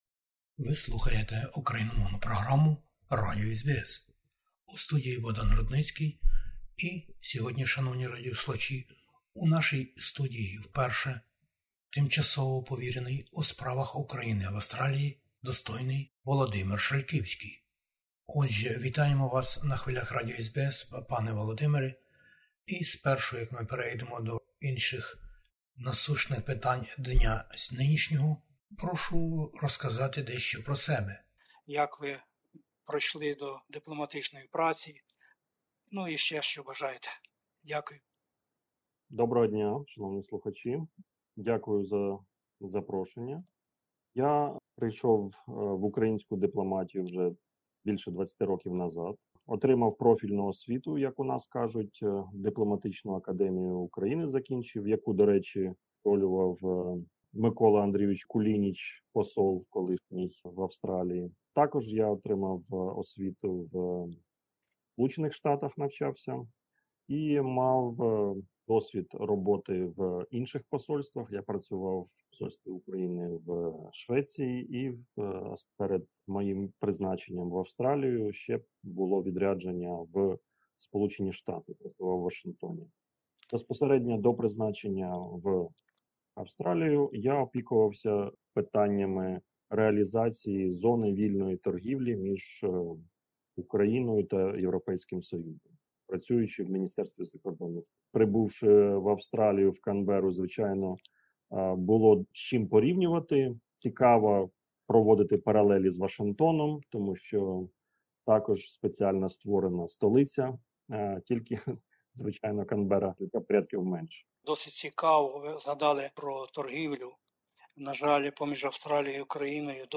Про це і більше - у розмові із Тимчасовим Повіреним у справах України в Австралії вельмишановним паном Володимиром Шальківським...